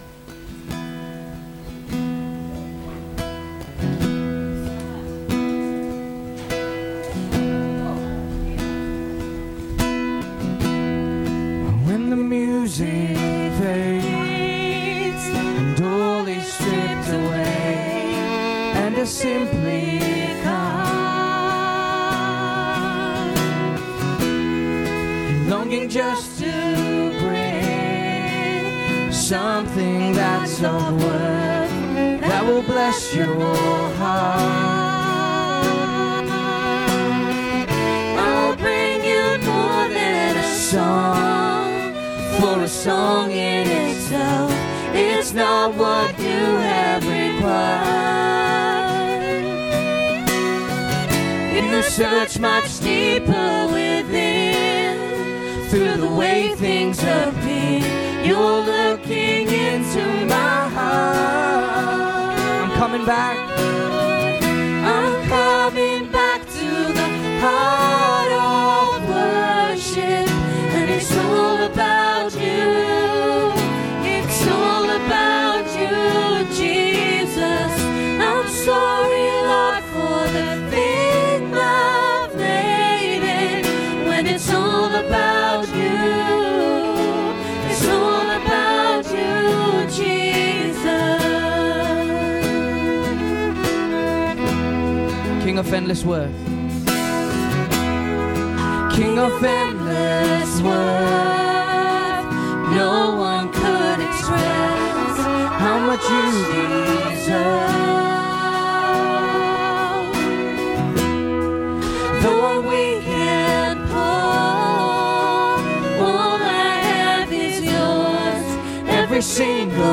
Please join us this Pentecost Sunday for the next in our sermon series 'We've got work to do!'
Service Audio